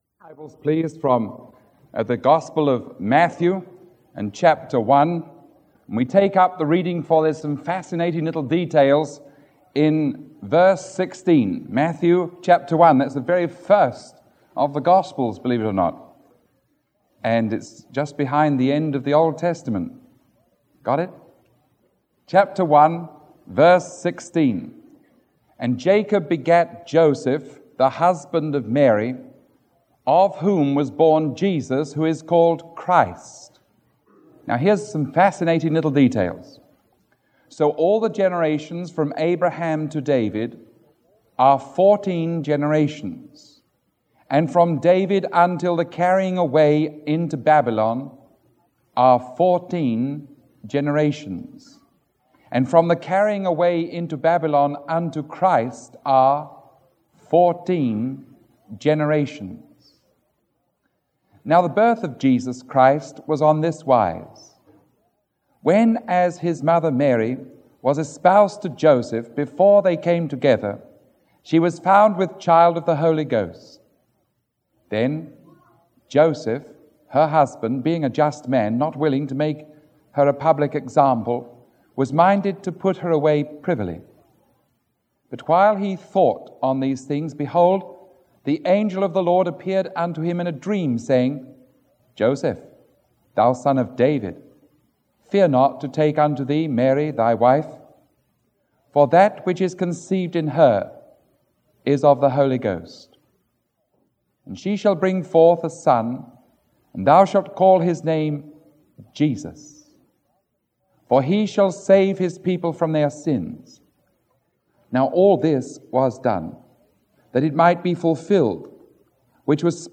Sermon 0462A recorded on December 13